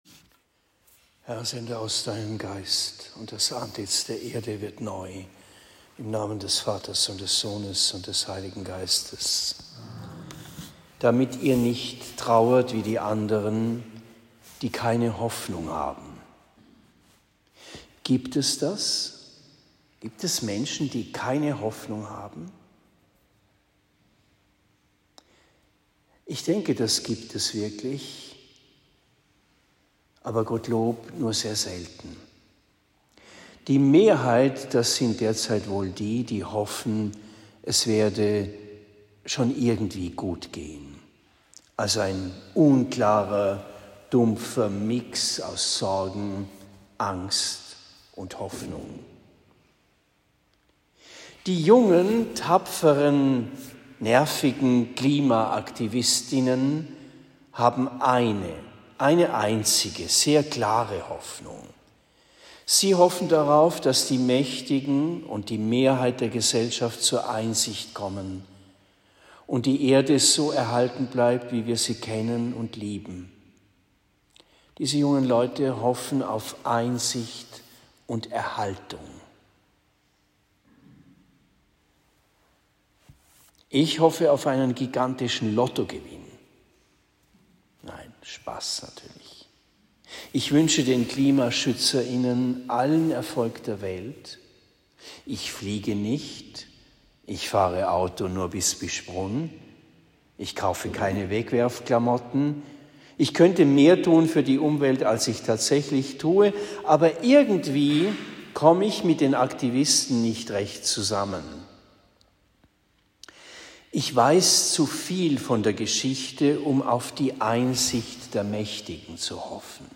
Predigt in Bischbrunn am 04. September 2023